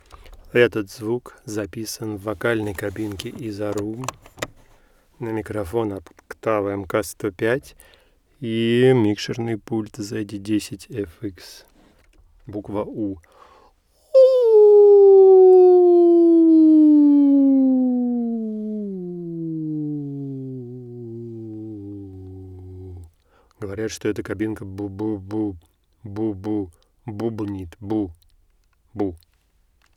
Вот как звучит мой голос в этой кабинке.